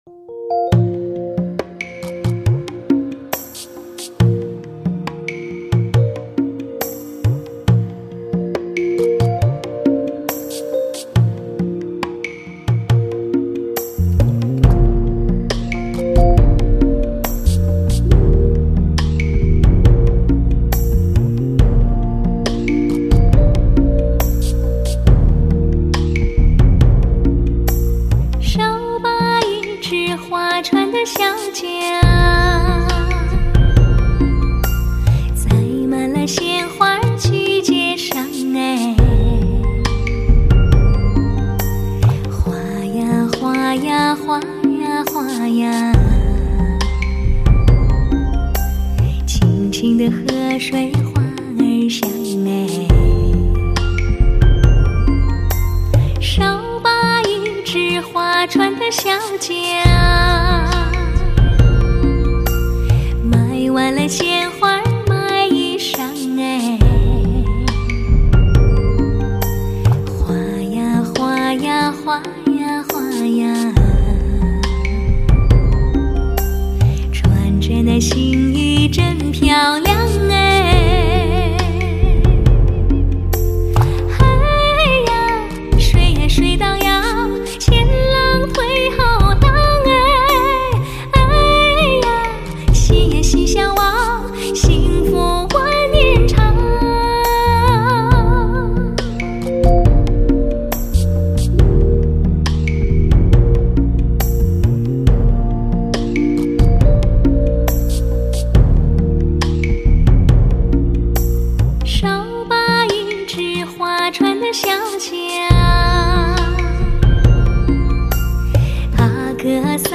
3D环绕高保真特效名车专用CD，玻璃母版直刻，高临场模拟技术，入微细节，音色超级宽阔，温暖醇厚。